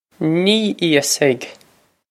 Pronunciation for how to say
Nee ee-us-hig
This is an approximate phonetic pronunciation of the phrase.